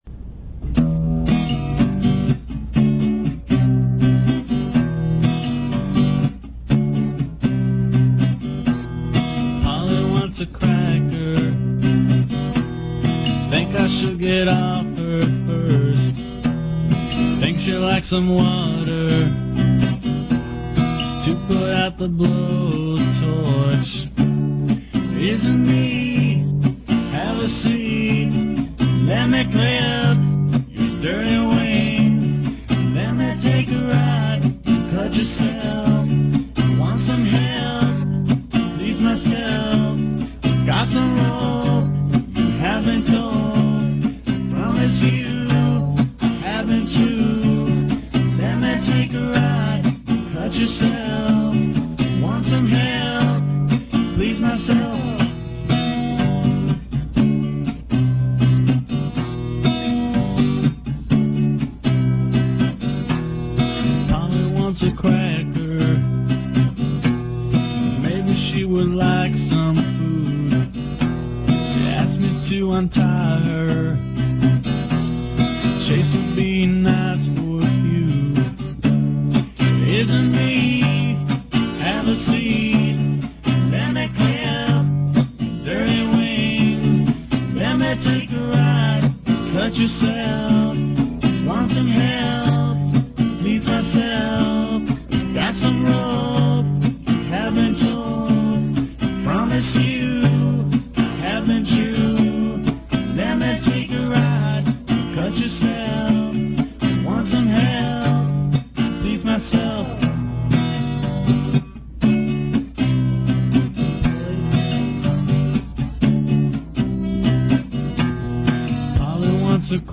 All of the existing versions were recorded in Chicago.